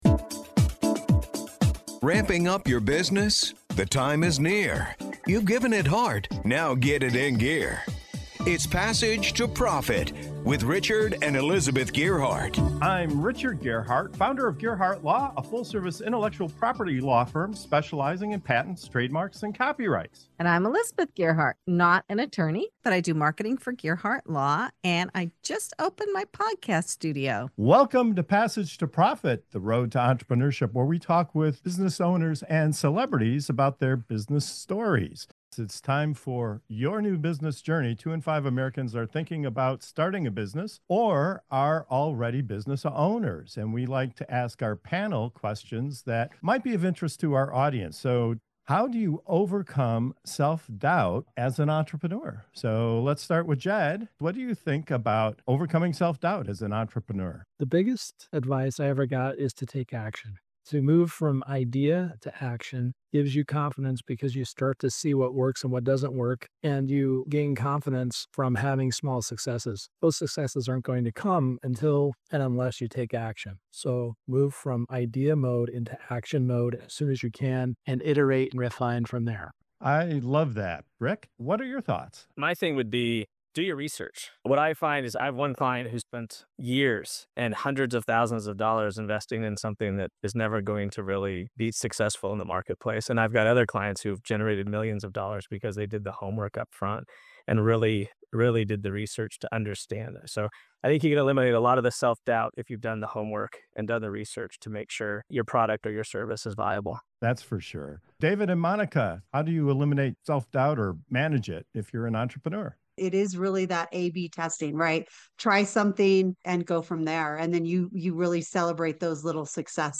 In this empowering segment of "Your New Business Journey" on Passage to Profit Show, our panel of seasoned entrepreneurs shares real talk on how to overcome self-doubt in business. From taking action and celebrating small wins to doing your homework and embracing the hustle beneath the surface, these insights will help you move from hesitation to confidence—one bold step at a time.